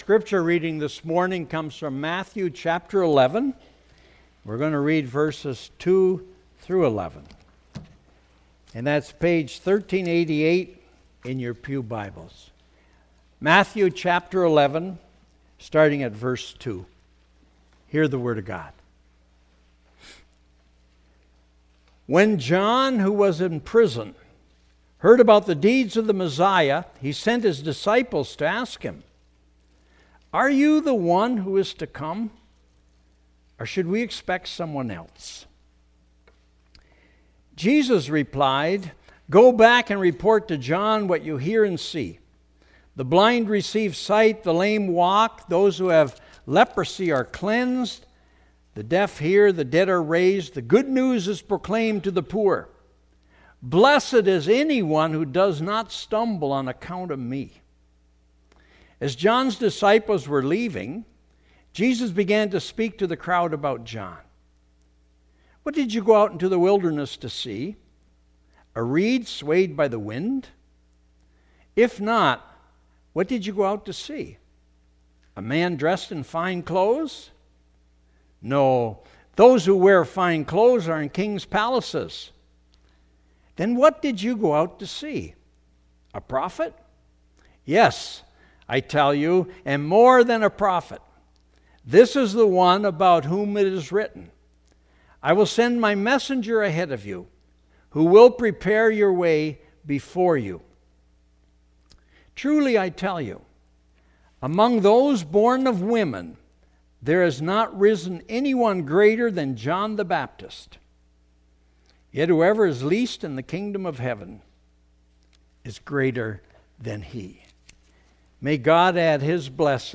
Sermons | Eighth Reformed Church